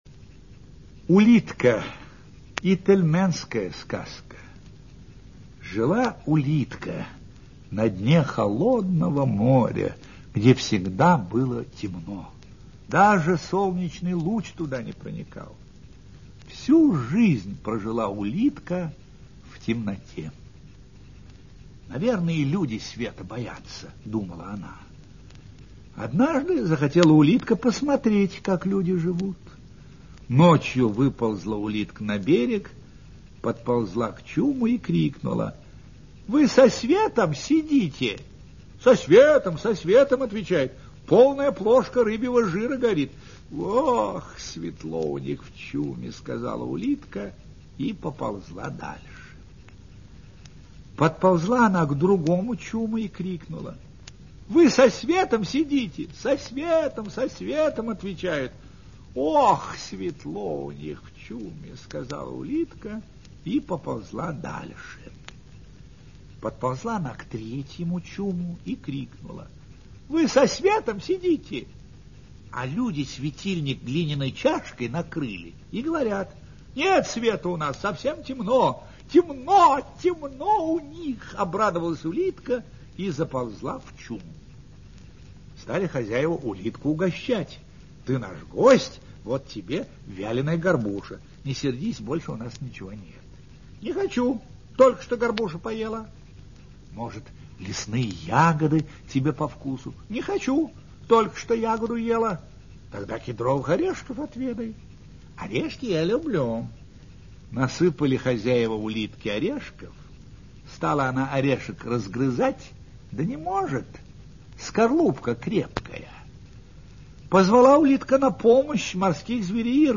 Улитка - ительменская аудиосказка - слушать онлайн
-- / -- volume_up volume_mute audiotrack Улитка - Народы России Текст читает Ростислав Плятт. 0 18 1 Добавлено в плейлист (избранное) Удалено из плейлиста (избранное) Достигнут лимит
ulitka-itelmenskaya-skazka.mp3